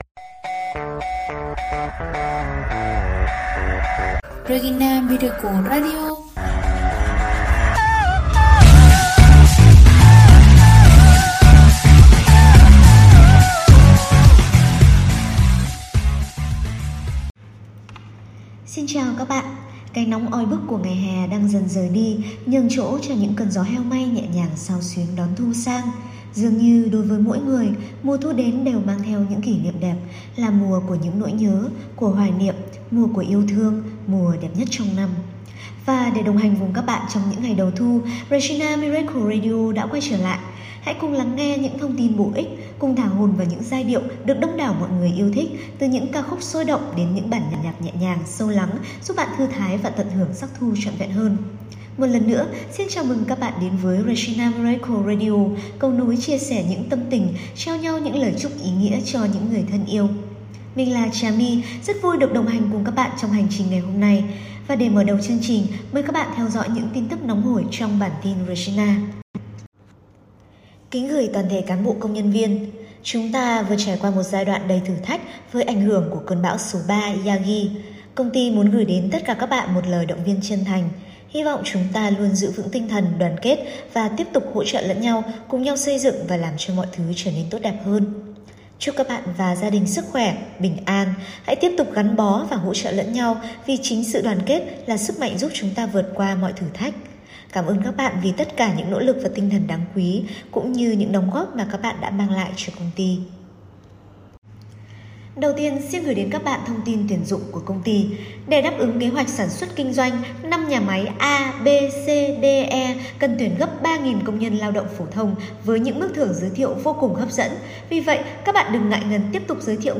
Khi những cơn gió heo may khẽ chạm vai, báo hiệu thu sang cũng là lúc Regina Miracle Radio trở lại, mang đến những giai điệu ngọt ngào và lời chúc thân thương gửi đến đại gia đình Regina 💌